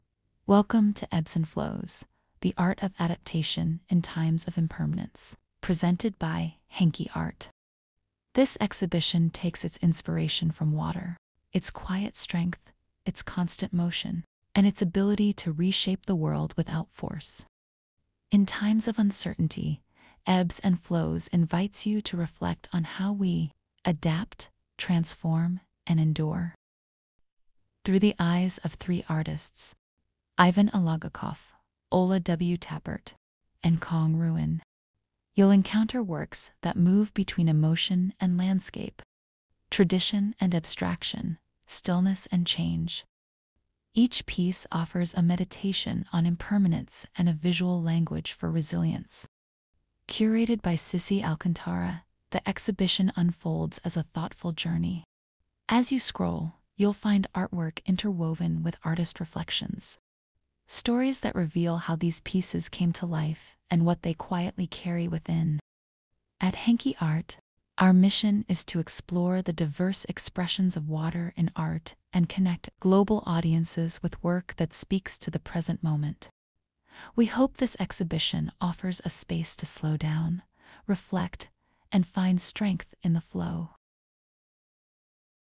🔊 Before you begin exploring Ebbs and Flows, we invite you to take a moment to listen to this short introduction. In just over a minute, you’ll hear the story behind the exhibition – its guiding themes, the artists involved, and what we hope you’ll take with you from this experience.